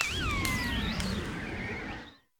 Cri de Virovent dans Pokémon Écarlate et Violet.